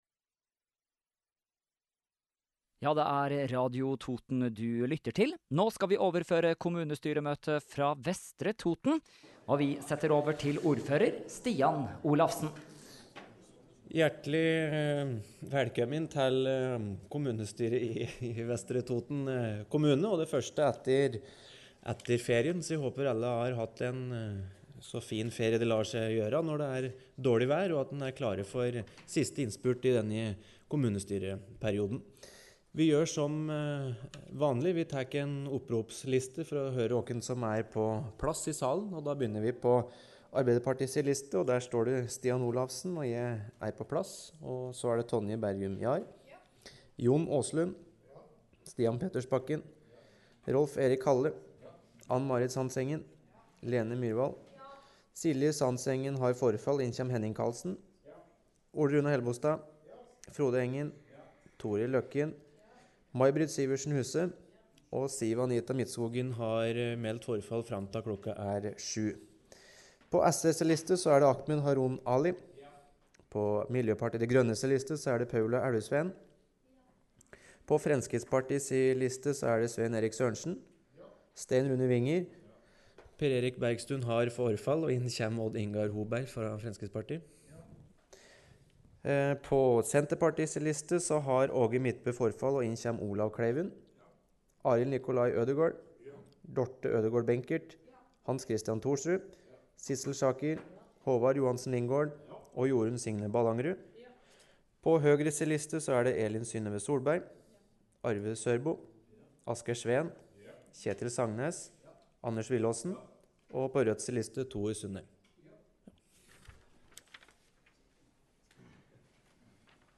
Kommunestyremøte i Vestre Toten 31. august – Lydfiler lagt ut | Radio Toten